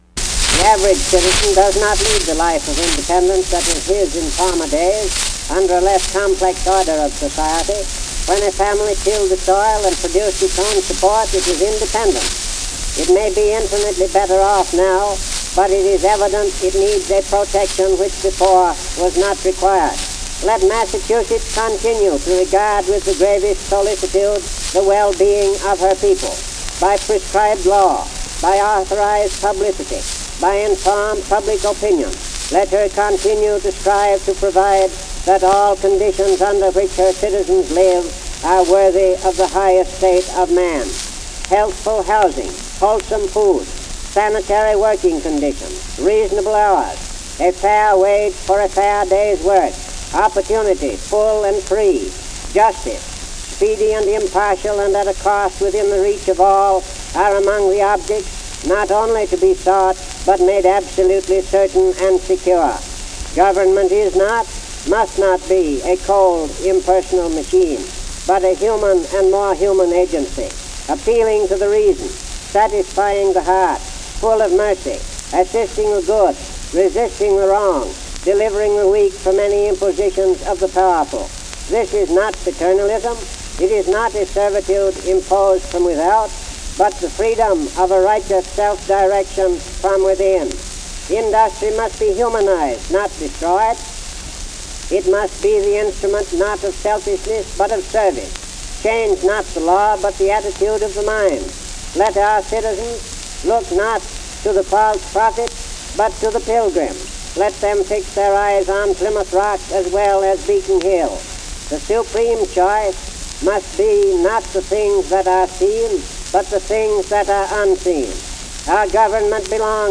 Law and order speech